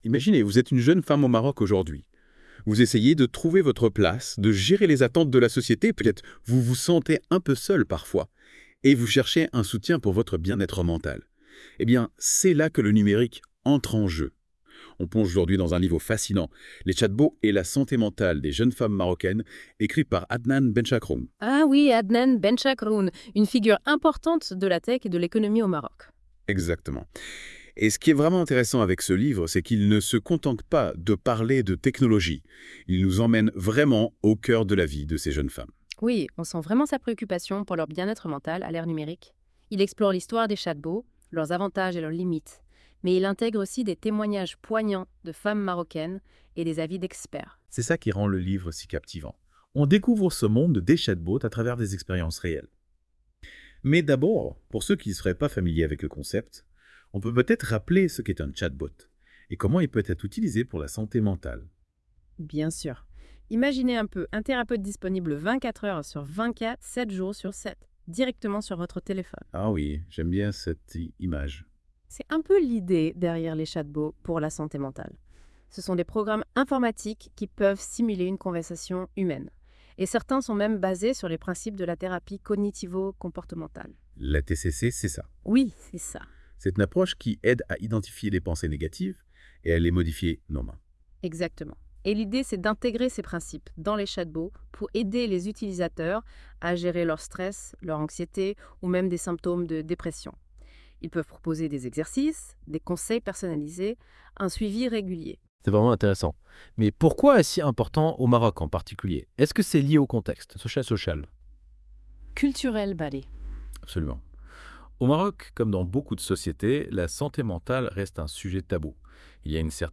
Débat (45.77 Mo) Quels sont les principaux avantages et inconvénients perçus des chatbots pour la santé mentale?